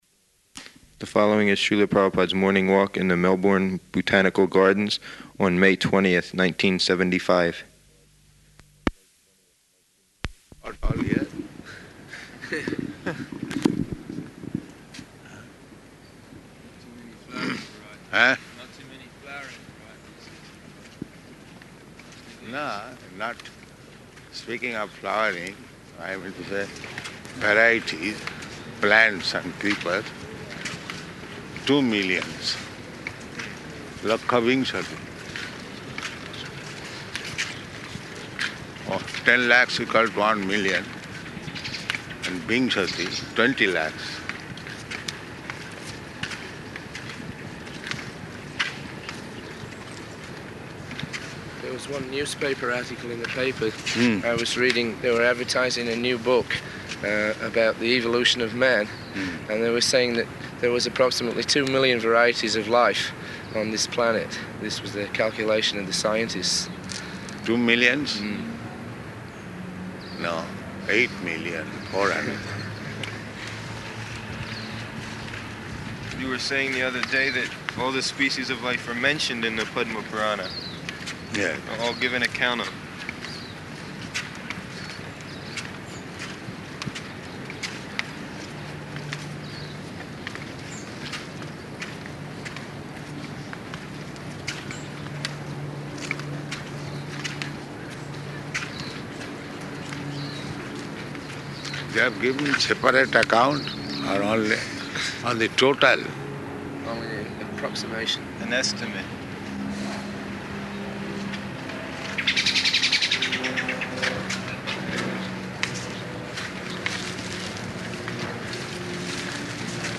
Morning Walk in the Botanical Gardens
Type: Walk
Location: Melbourne